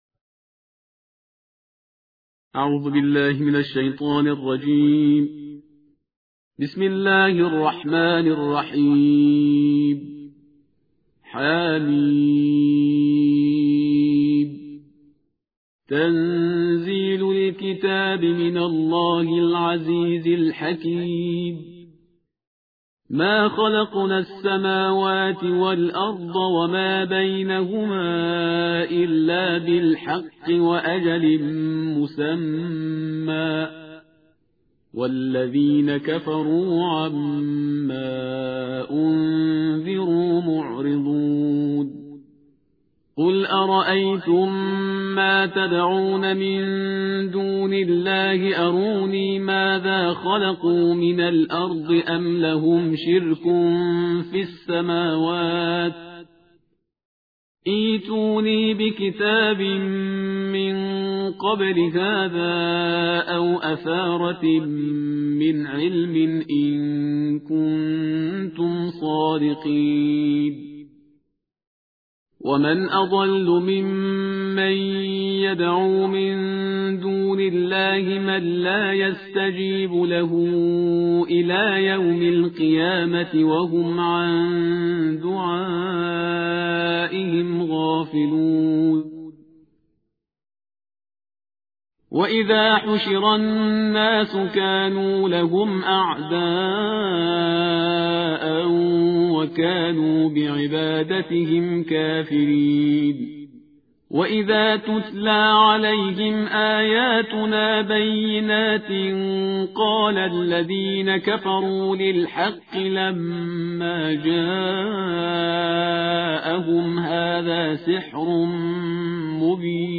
ترتیل جزءبیست و شش قرآن کریم/استاد پرهیزگار